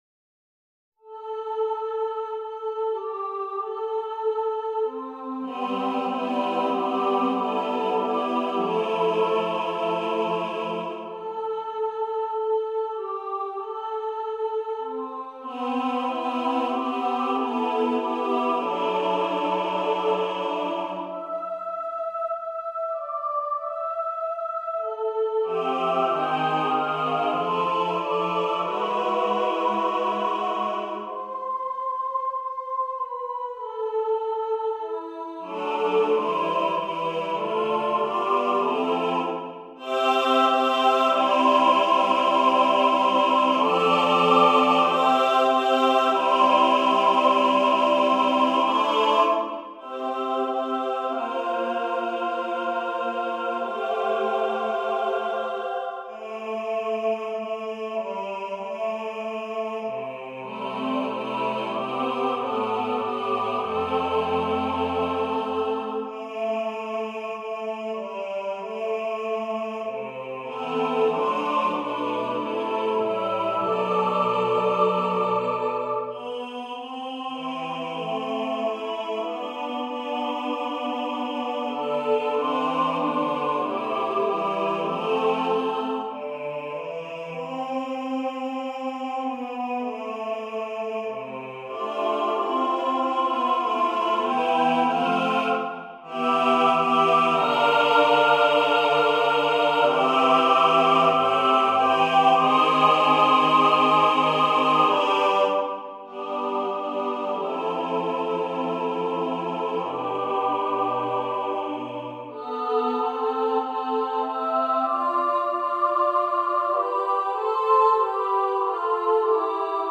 SATB, a capella